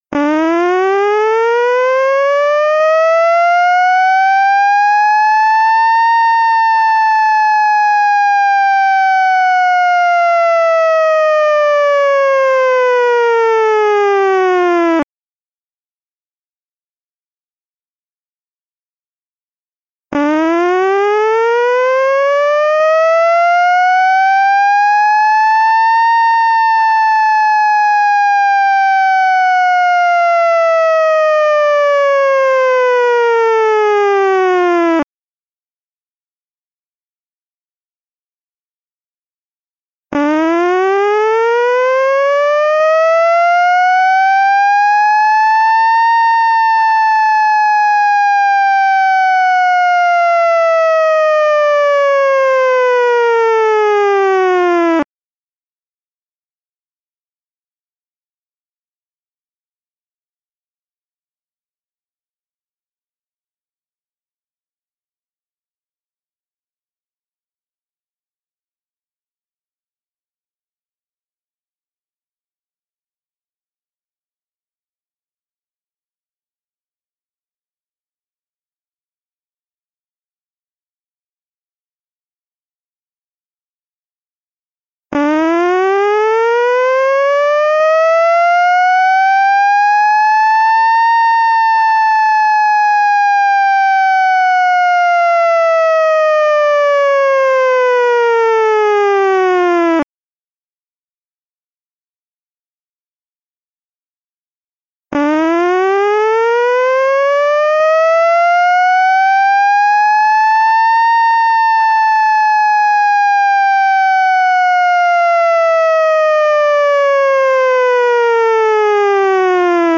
帷子川と今井川の合流点（今井川河口橋付近）に水位計を設置し、洪水の危険を「２段階のサイレン音」により、周辺地域の皆様方にお知らせするシステムです。
第１段階サイレンの試聴（音楽ファイル(MP3)：1,094KB）